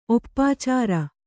♪ oppācāra